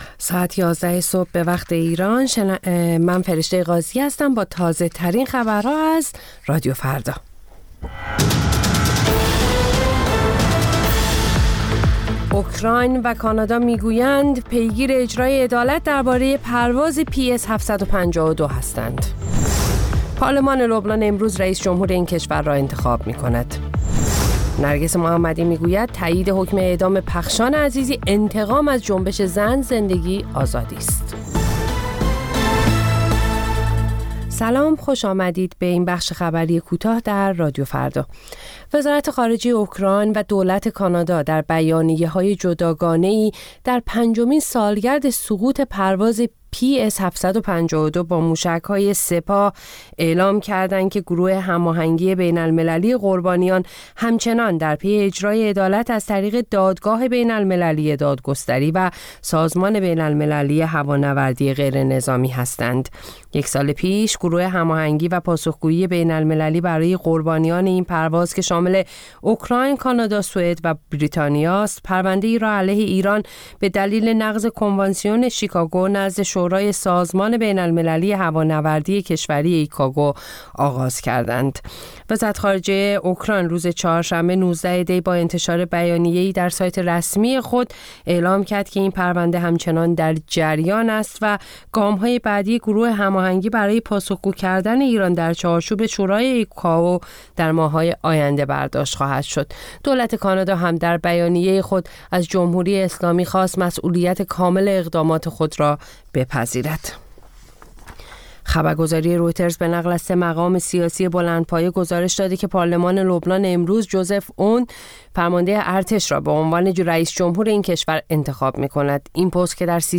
سرخط خبرها ۱۱:۰۰